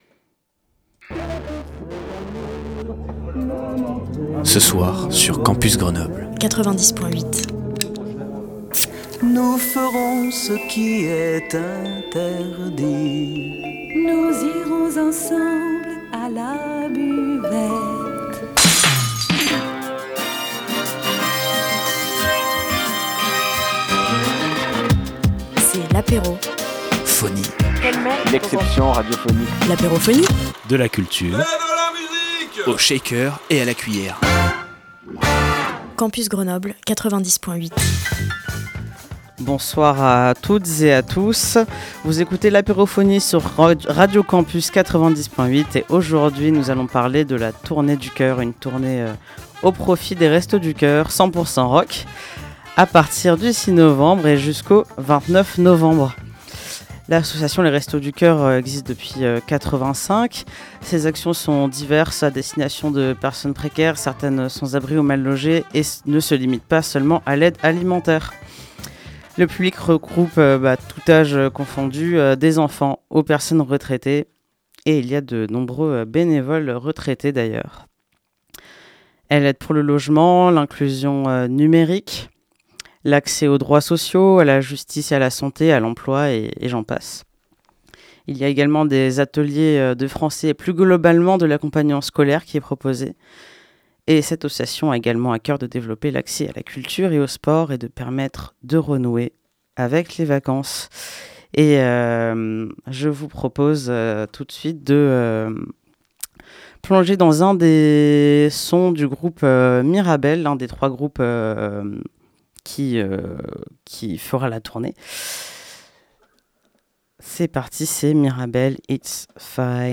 Dans cette nouvelle apérophonie, nous vous présentons la tournée du cœur qui s’étend du 6 au 29 novembre 2025, une tournée au profit des restos du cœur. Nous avons comme invité 2 des 3 groupes de la tournée : Celkilt et Mirabelle qui nous raconte l’histoire de leur groupe respectif, de la création de la tournée, de leur projets à venir, …